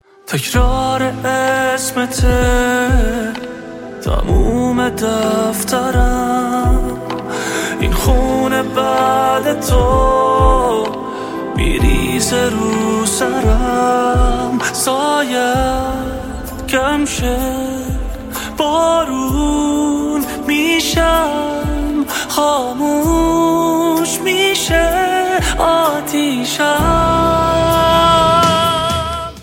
آهنگ زنگ غمگین سوزناک